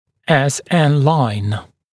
[es en laɪn][эс эн лайн]SN-линия (линия между селле и низионом)